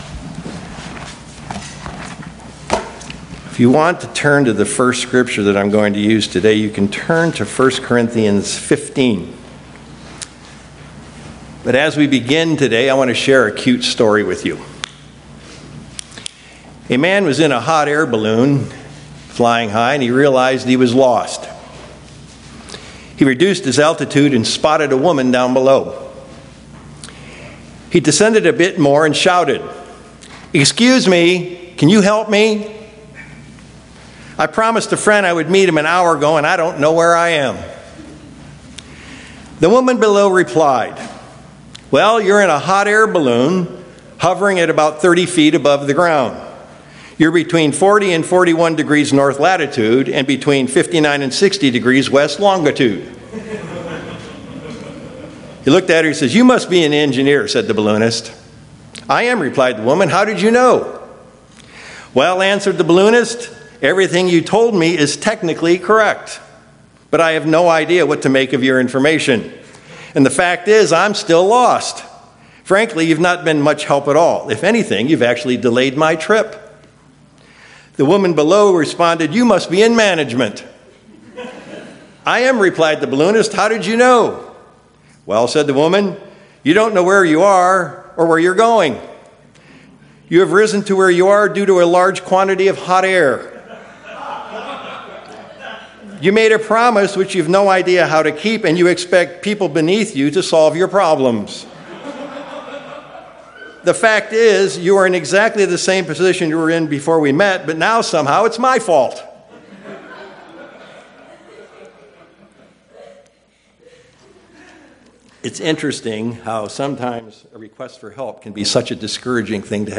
Given in Sacramento, CA
View on YouTube UCG Sermon Studying the bible?